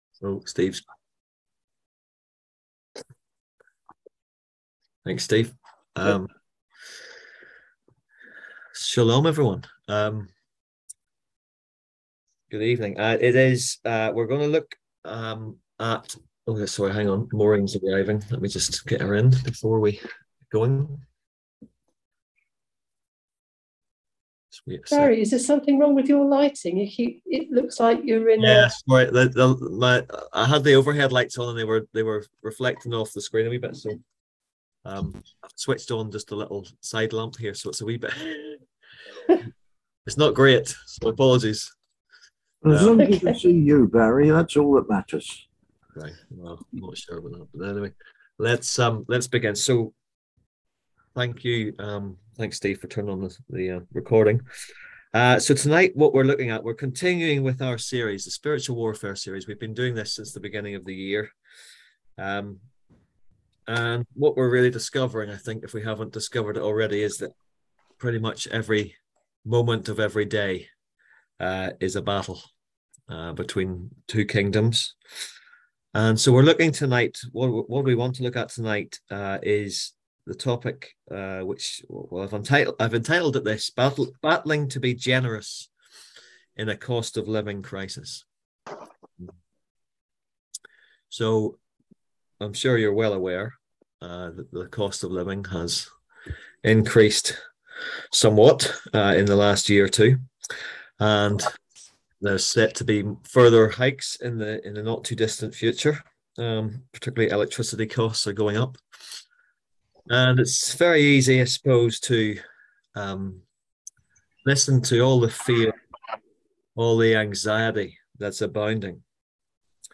On September 26th at 7pm – 8:30pm on ZOOM